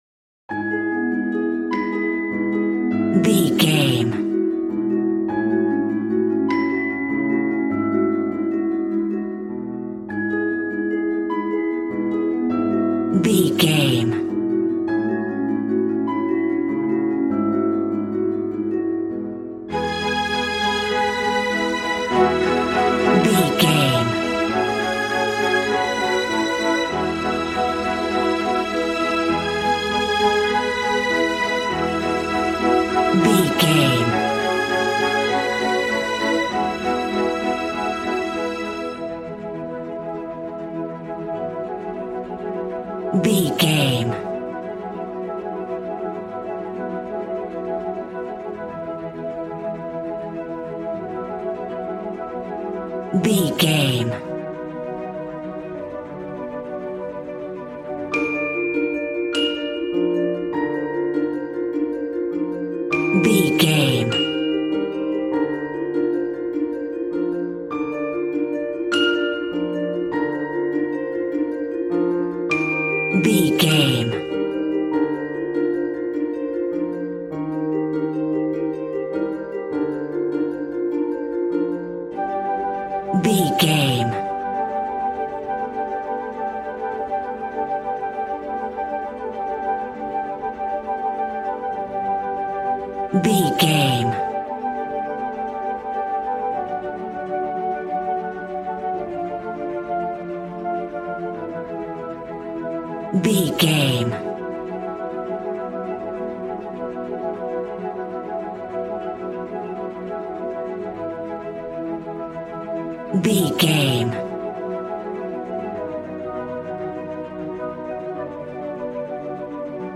Regal and romantic, a classy piece of classical music.
Ionian/Major
regal
strings
violin
brass